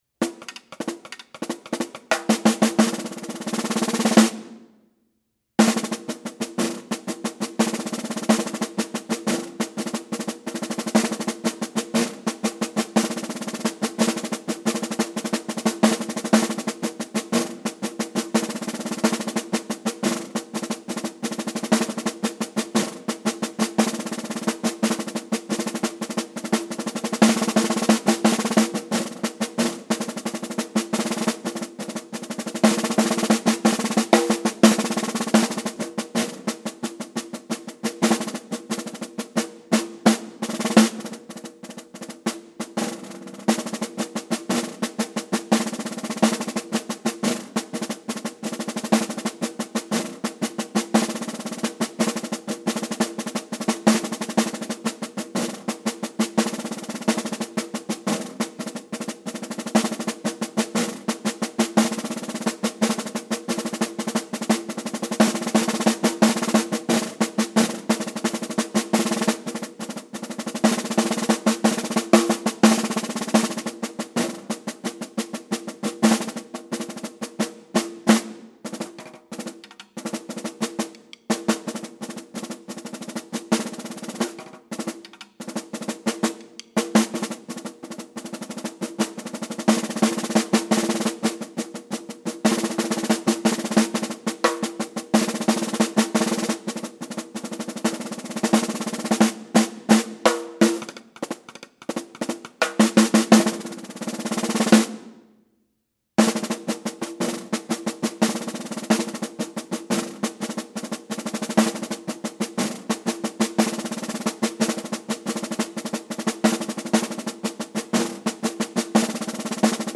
Voicing: Snare Drum Collection